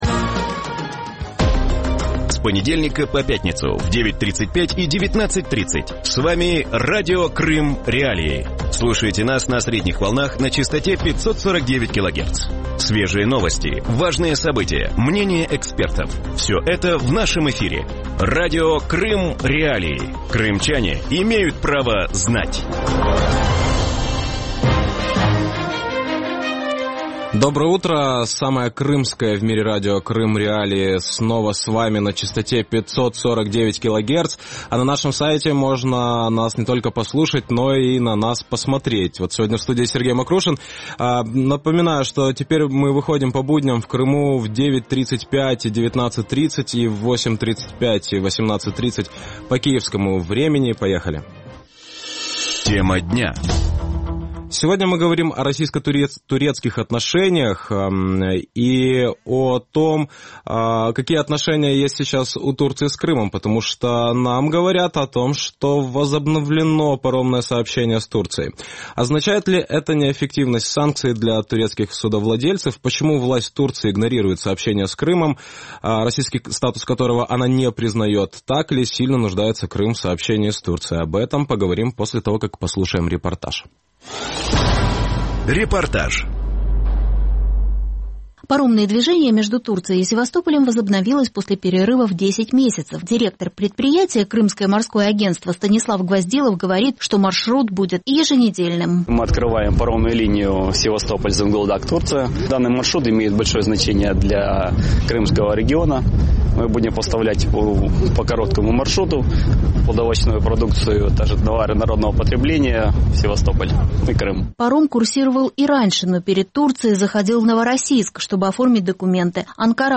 Вранці в ефірі Радіо Крим.Реалії говорять про Крим в контексті російсько-турецьких відносин. На півострові заявили про відновлення паромного сполучення з Туреччиною. Чи означає це неефективність санкцій для турецьких судновласників?